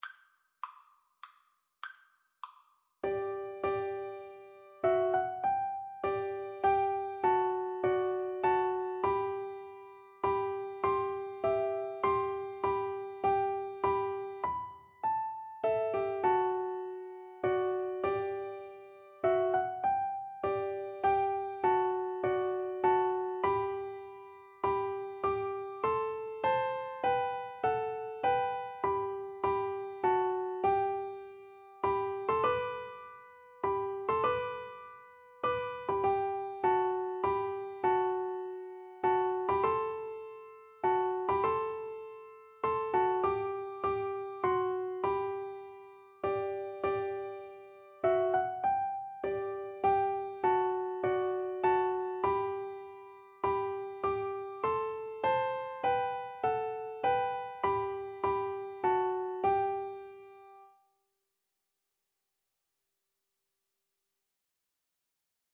Traditional William H. Doane To God Be the Glory (Doane) Piano Four Hands (Piano Duet) version
3/4 (View more 3/4 Music)
G major (Sounding Pitch) (View more G major Music for Piano Duet )
Traditional (View more Traditional Piano Duet Music)